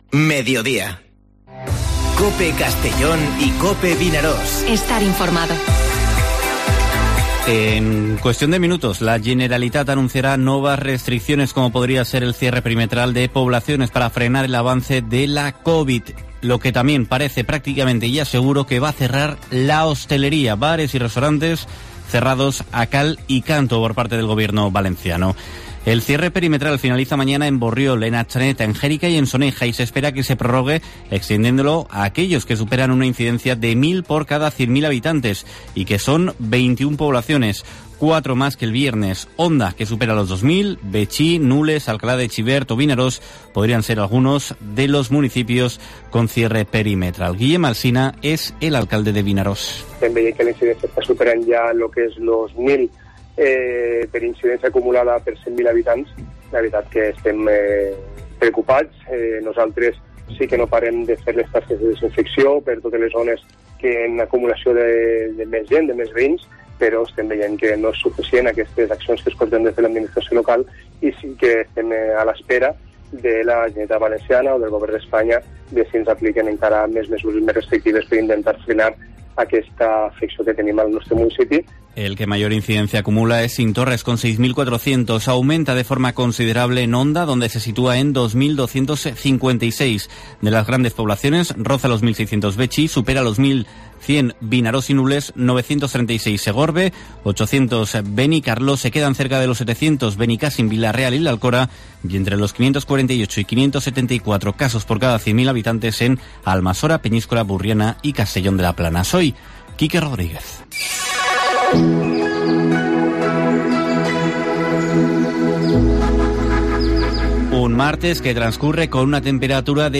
Informativo Mediodía COPE en la provincia de Castellón (19/01/2021)